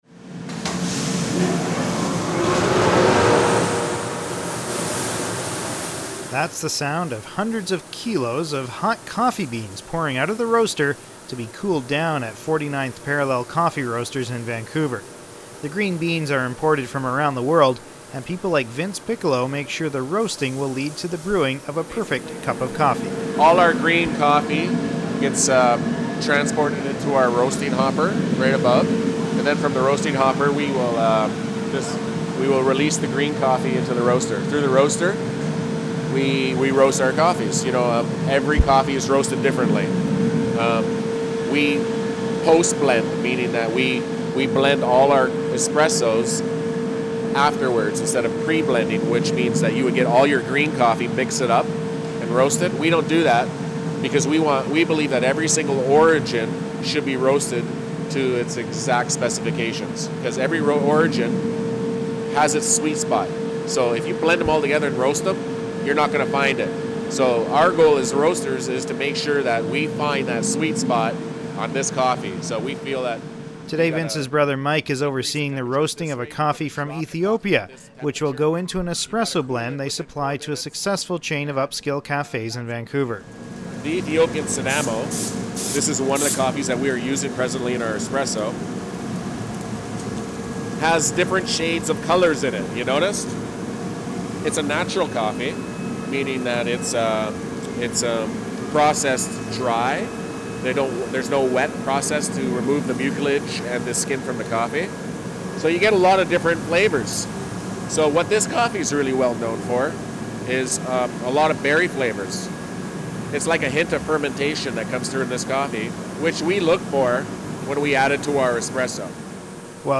Img_0103This week on Food For Thought, why the roasting of green coffee beans is so important to making a great cuppa joe. To listen to the 6 minute documentary, click